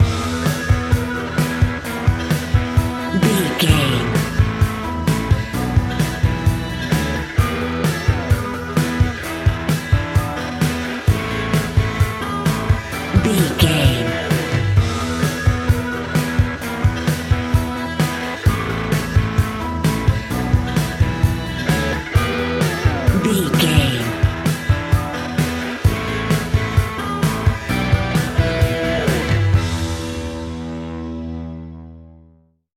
Ionian/Major
hard rock
blues rock
distortion
instrumentals